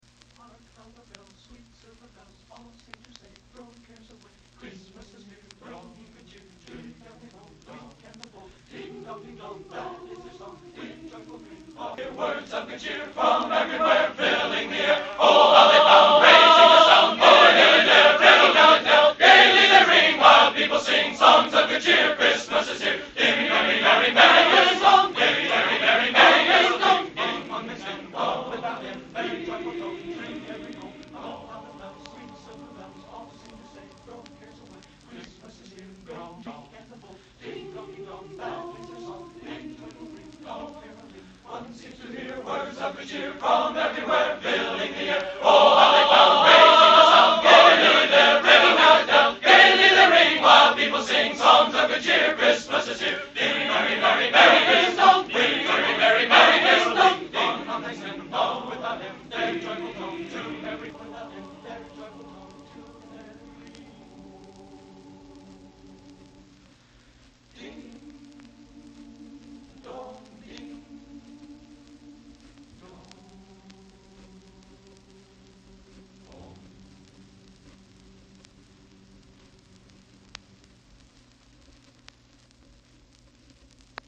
Genre: Holiday | Type: Christmas Show